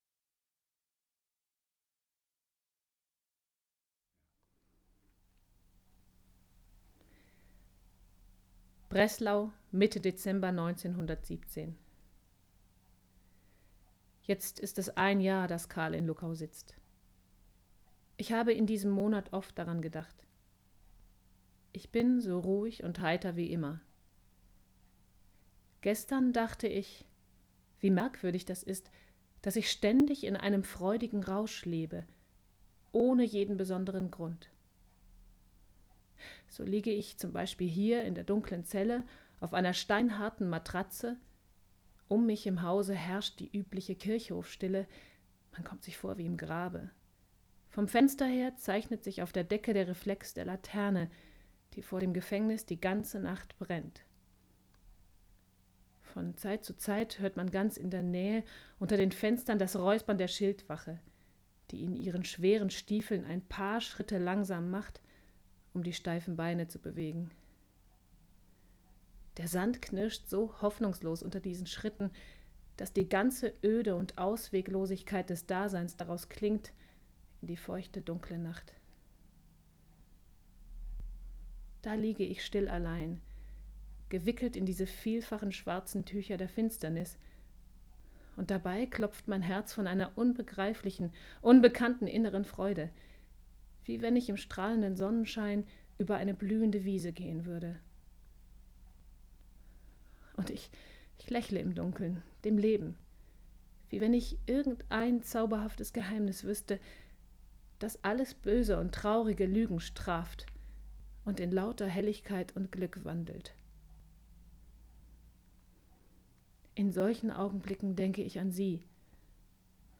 Hier findest du eine Auswahl verschiedener Stimmfarben und Sprachen, die ich bedienen kann: